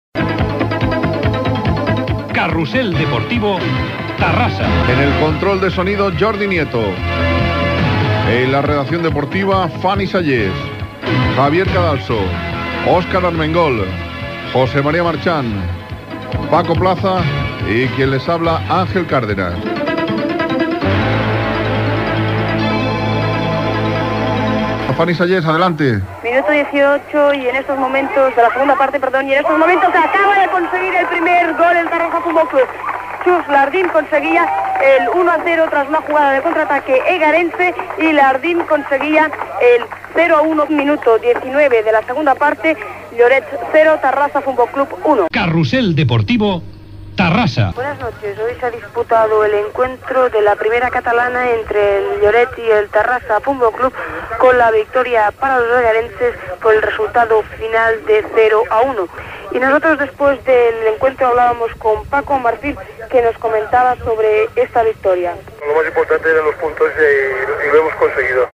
Carrusel Deportivo: transmissió futbol - Ràdio Terrassa, 1992